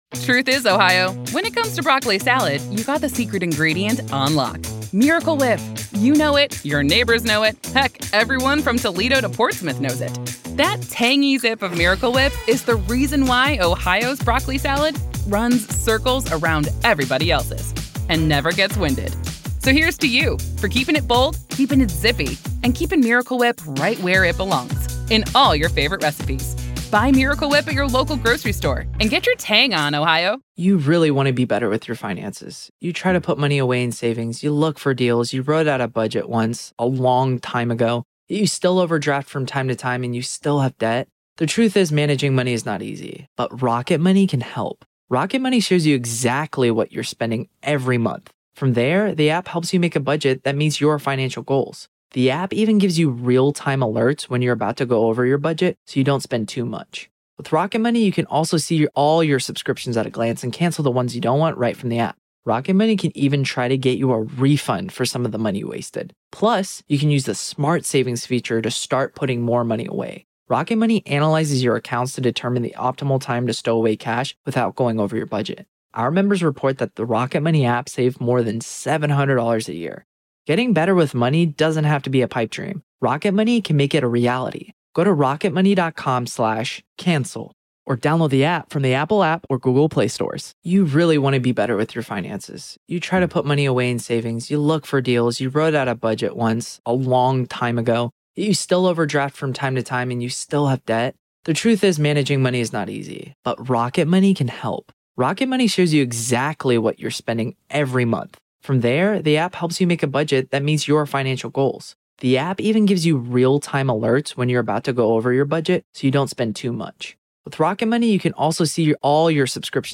LIVE COURTROOM COVERAGE — NO COMMENTARY